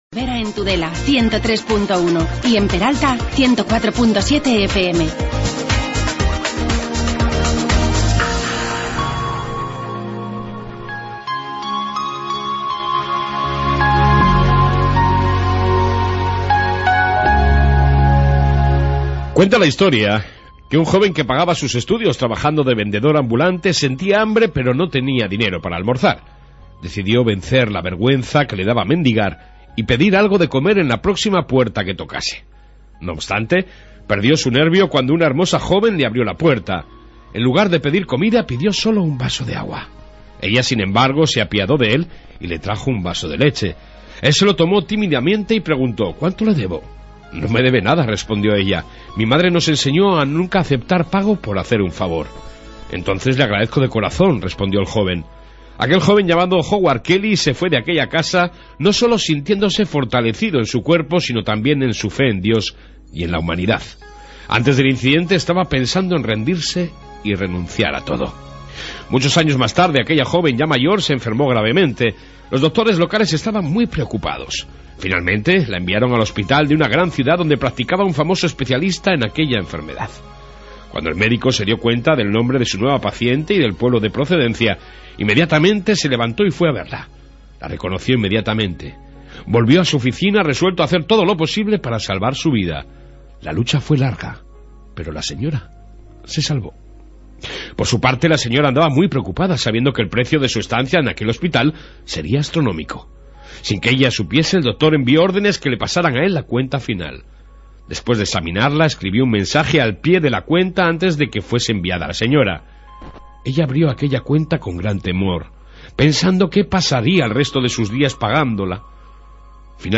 AUDIO: En esta 1 parte Informativo y reportaje Dolores Redondo